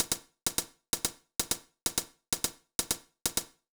INT Beat - Mix 15.wav